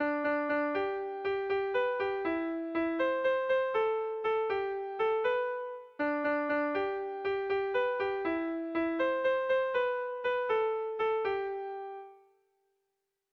Kontakizunezkoa
A1A2